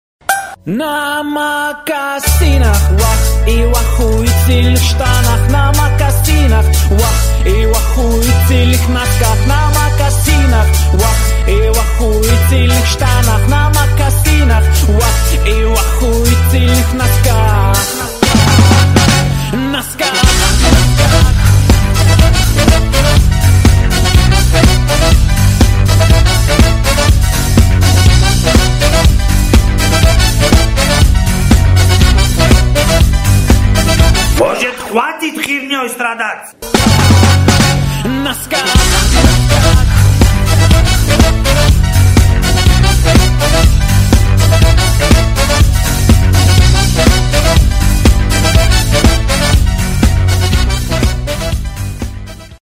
• Качество: 128, Stereo
смешные